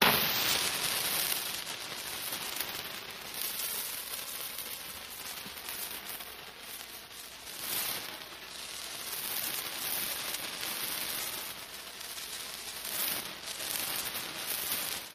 Fuses Burning 2